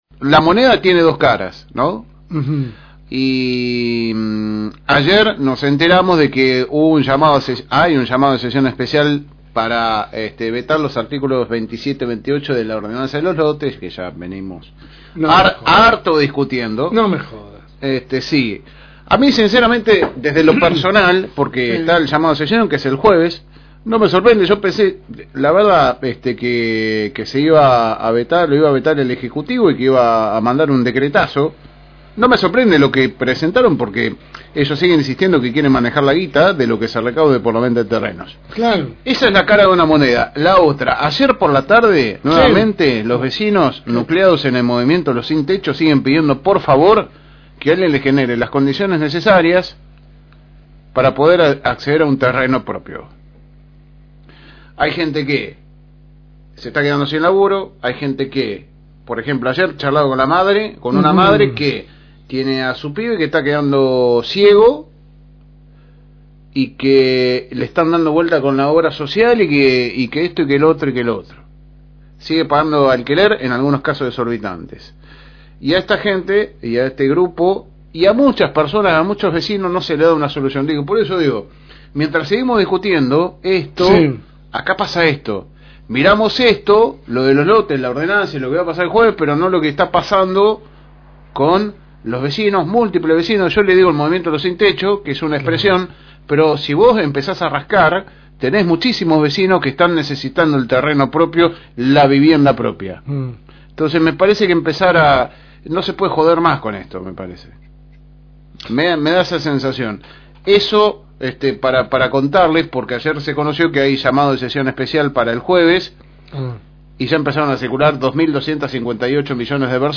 AUDIO – Editorial de LSM